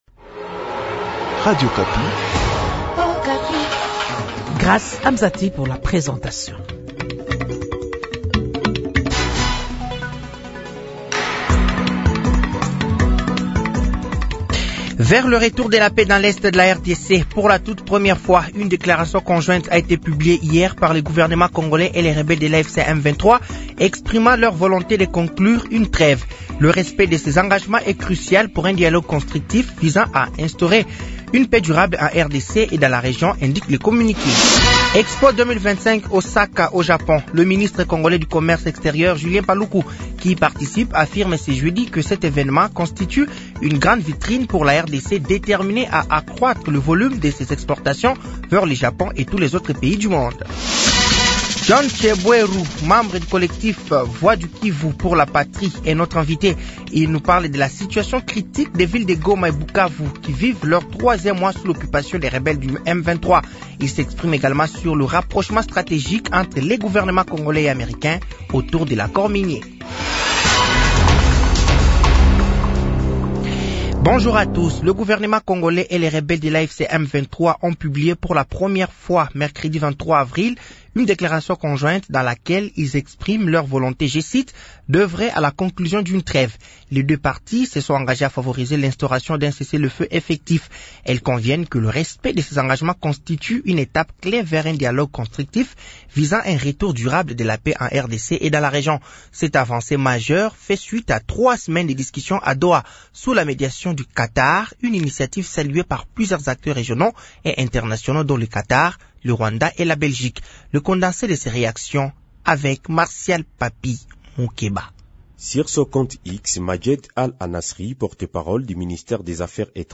Journal français de 15h de ce jeudi 24 avril 2025